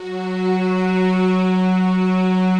Index of /90_sSampleCDs/Optical Media International - Sonic Images Library/SI1_Swell String/SI1_Soft Swell